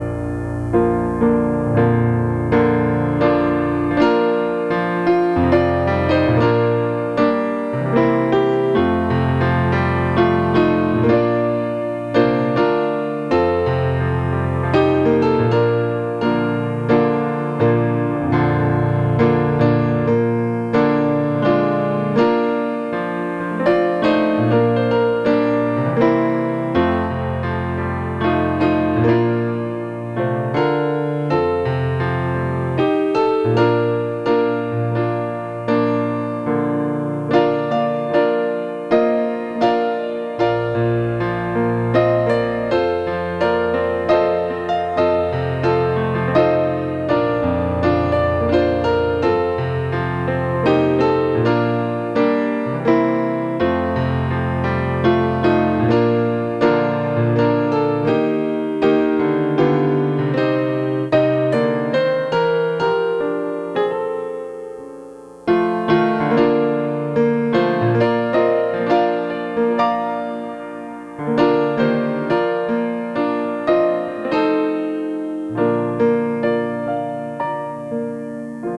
Piano Music - July 4, 2021